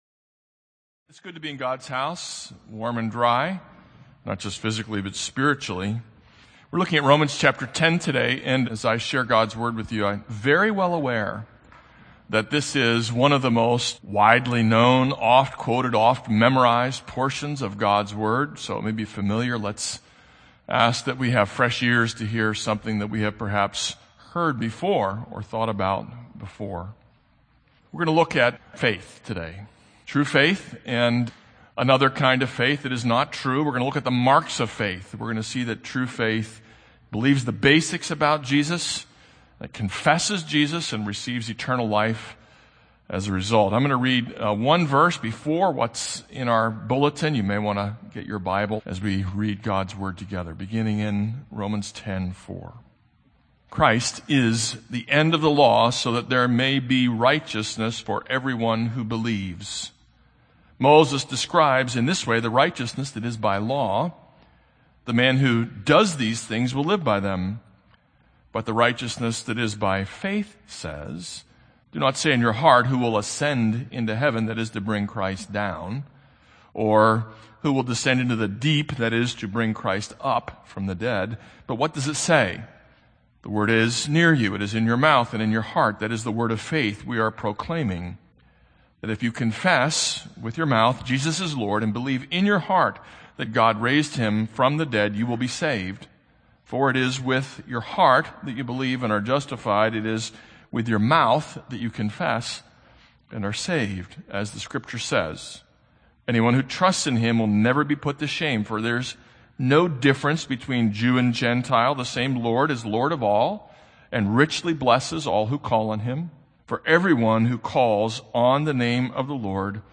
This is a sermon on Romans 10:5-13.